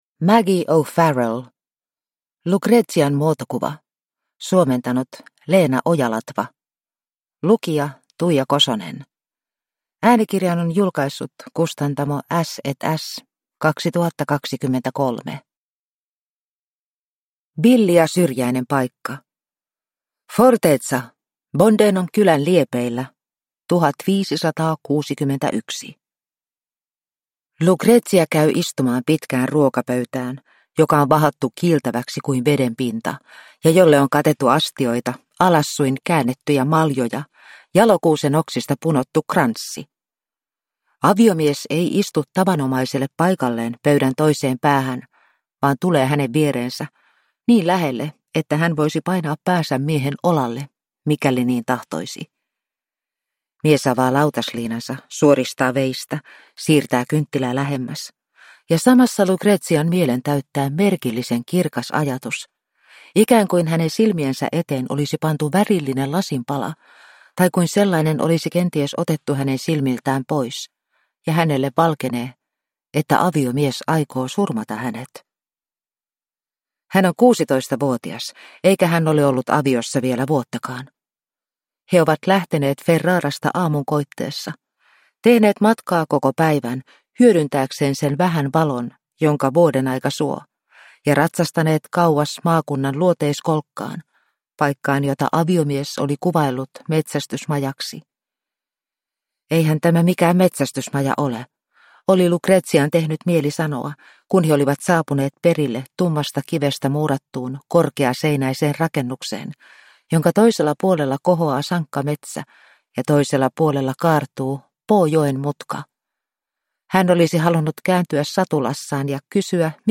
Lucrezian muotokuva – Ljudbok – Laddas ner